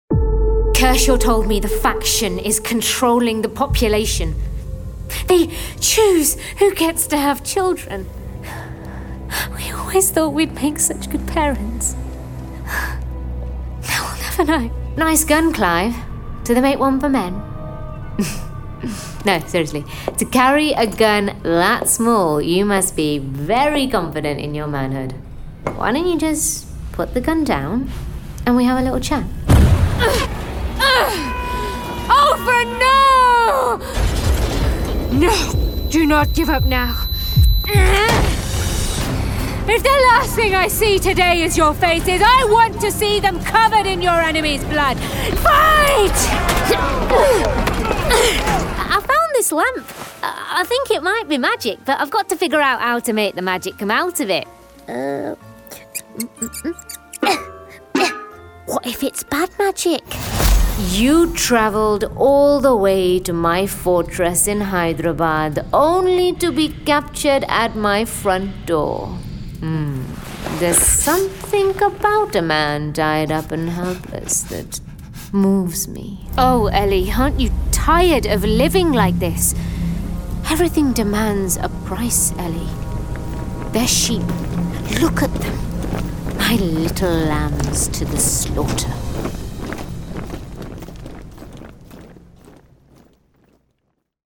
Anglais (Britannique)
Naturelle, Enjouée, Urbaine, Amicale, Chaude